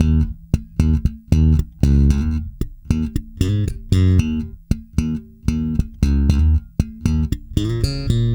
-JP THUMB D#.wav